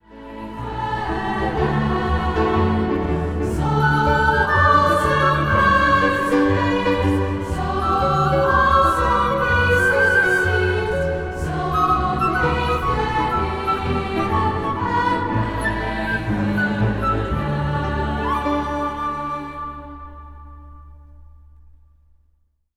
vleugel
orgel
Strijkkwintet
Zang | Gemengd koor
Zang | Meisjeskoor